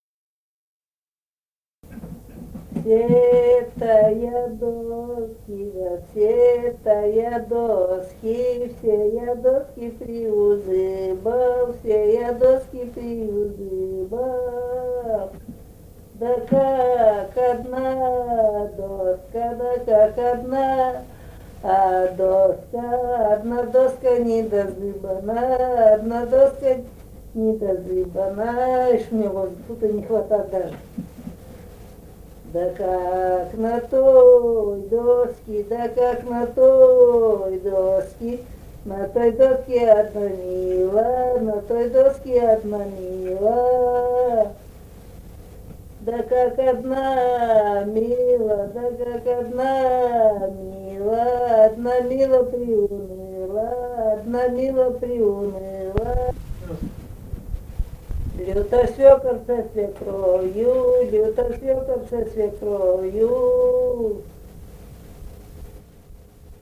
Русские песни Алтайского Беловодья 2 «Все-то я доски приузыбал», «лужошная».
Республика Казахстан, Восточно-Казахстанская обл., Катон-Карагайский р-н, с. Белое, июль 1978.